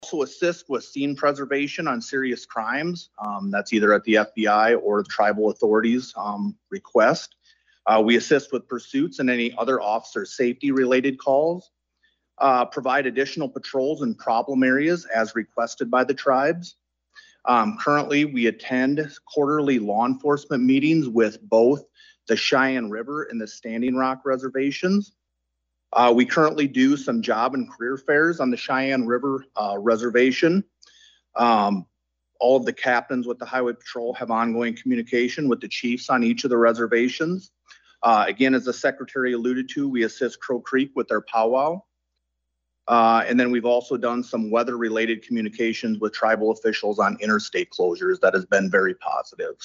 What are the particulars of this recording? AGENCY VILLAGE, S.D.(HubCityRadio)- On Thursday, the first meeting of the Interim State Tribal Relations Committee was held at the Sisseton-Wahpeton Oyate Headquarters at Agency Village.